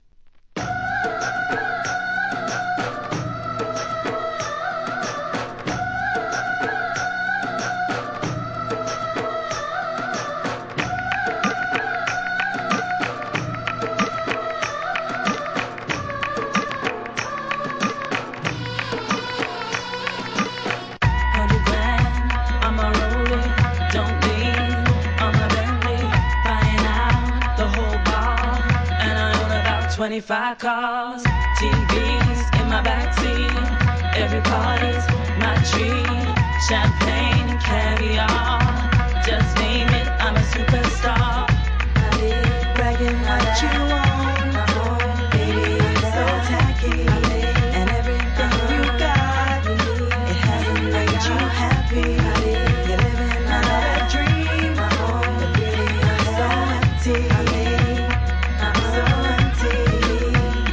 怒エスニックR&B!!